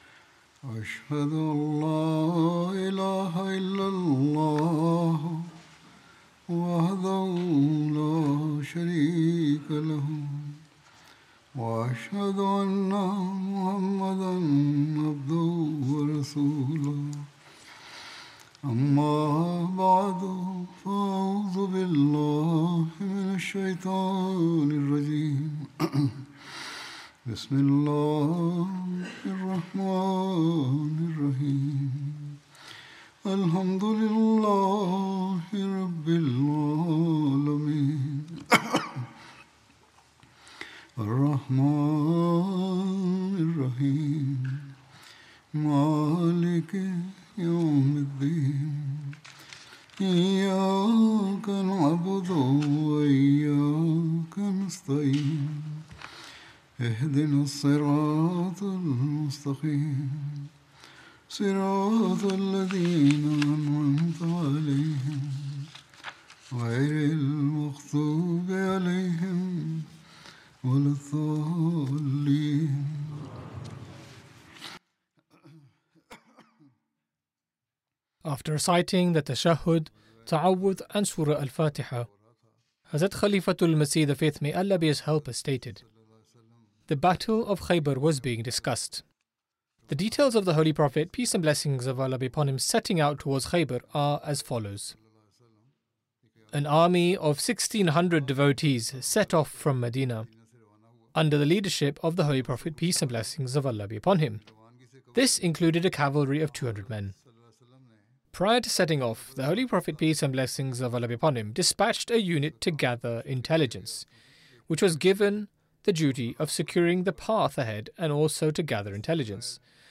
English Friday Sermon by Head of Ahmadiyya Muslim Community
English Translation of Friday Sermon delivered by Khalifatul Masih